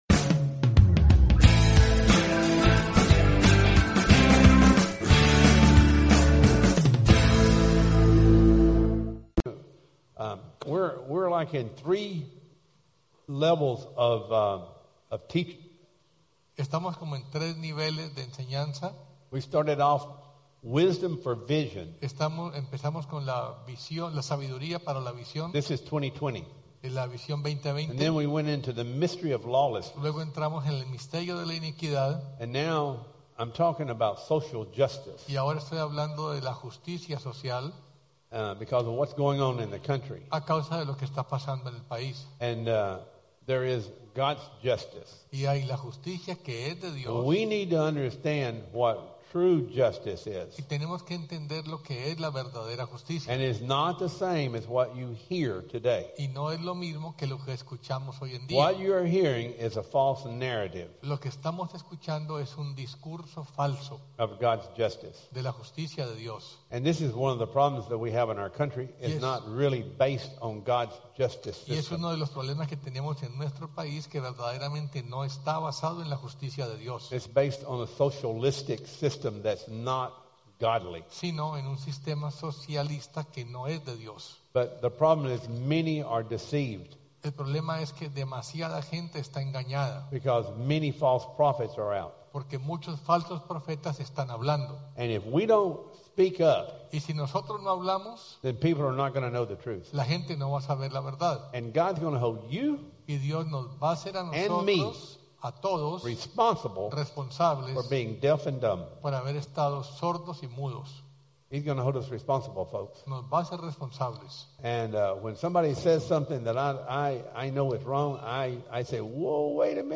Wisdom for Vision Service Type: Sunday Service « Justice or Social Justice?